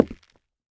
Minecraft Version Minecraft Version latest Latest Release | Latest Snapshot latest / assets / minecraft / sounds / block / nether_wood / step4.ogg Compare With Compare With Latest Release | Latest Snapshot
step4.ogg